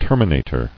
[ter·mi·na·tor]